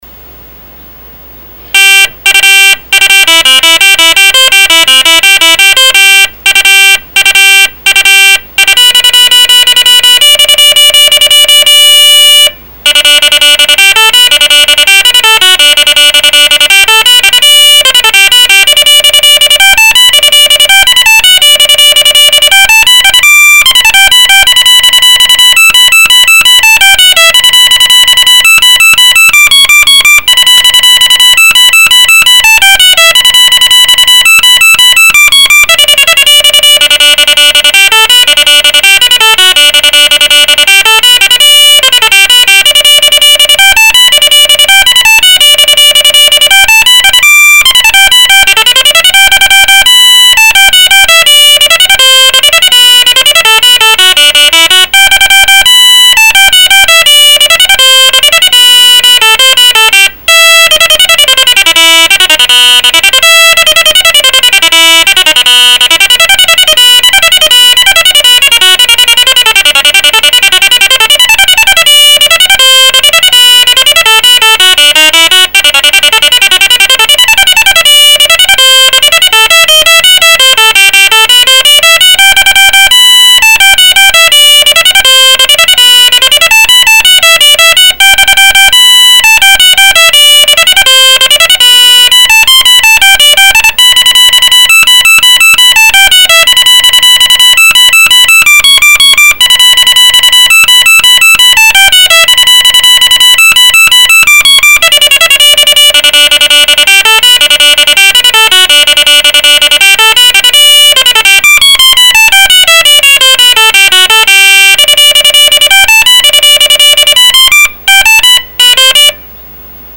The HP-85 Standard Pac included a program called COMPZR which allowed the user to edit and play music composed of single notes using the internal speaker and the BEEP command.
Audio recorded as my HP-85B was playing the piece (